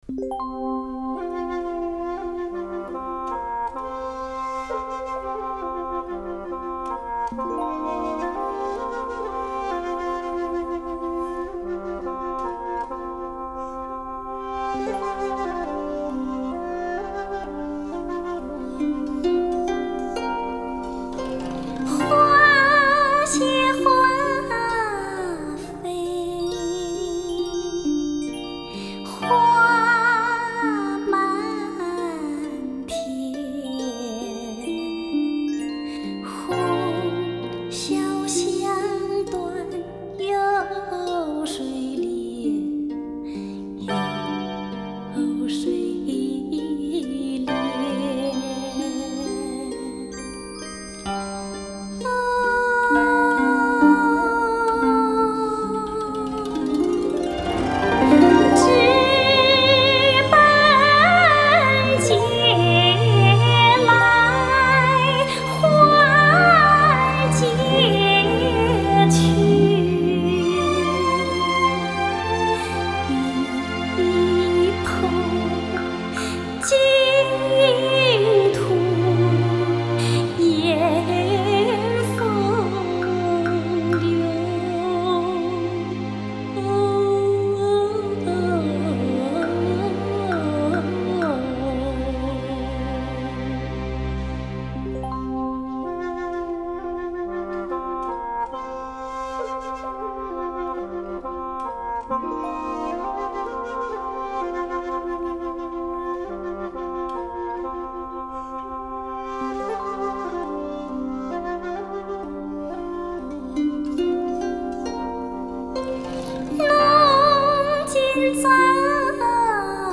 融汇中国古曲精髓与西洋作曲手法的经典力作。